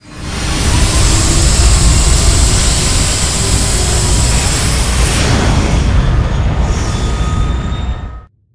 launch1.ogg